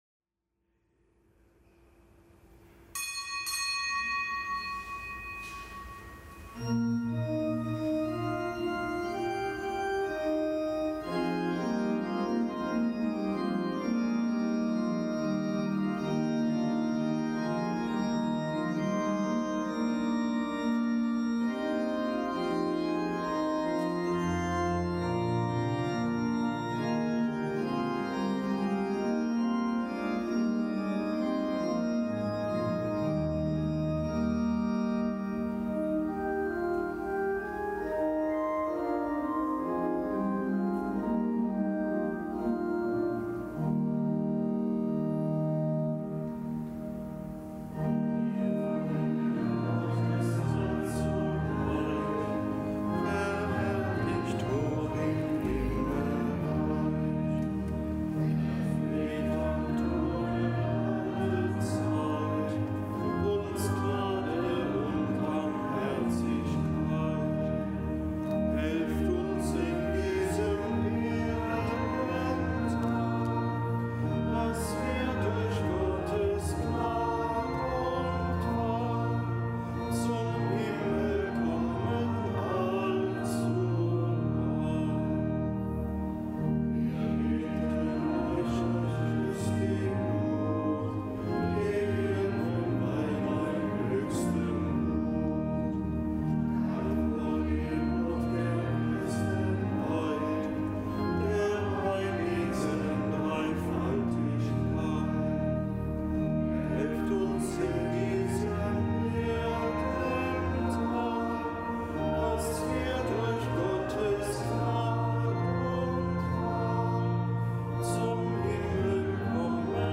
Kapitelsmesse am Gedenktag des Heiligen Martin